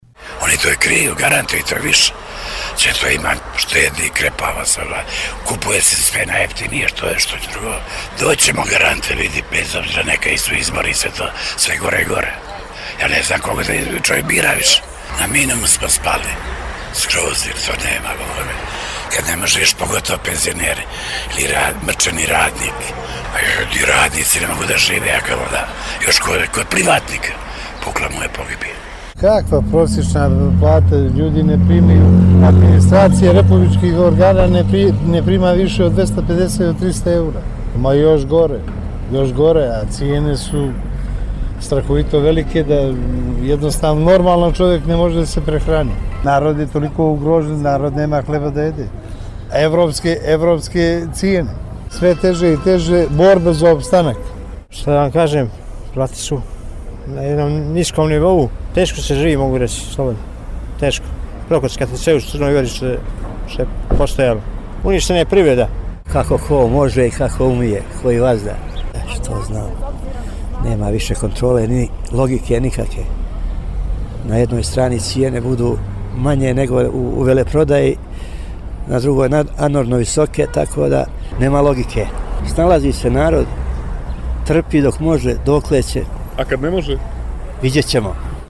Pogoričani o potrošačkoj korpi